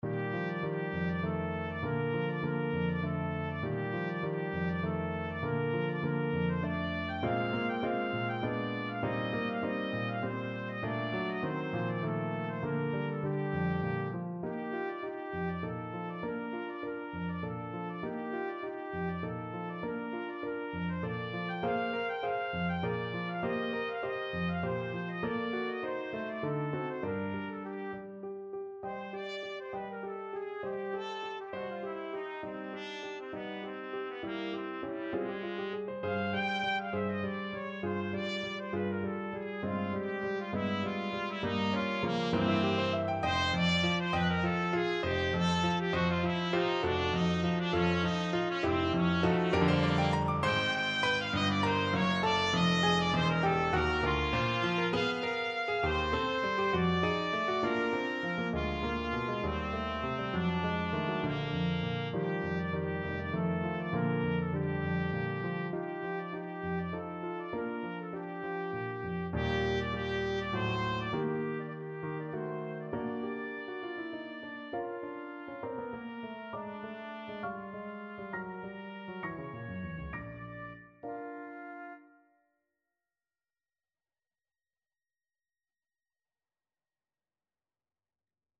Trumpet
3/4 (View more 3/4 Music)
G4-G6
Bb major (Sounding Pitch) C major (Trumpet in Bb) (View more Bb major Music for Trumpet )
Allegro giusto =200 (View more music marked Allegro)
Classical (View more Classical Trumpet Music)